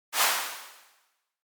SE（風）
さーっ。サーッ。風。